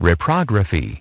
Транскрипция и произношение слова "reprography" в британском и американском вариантах.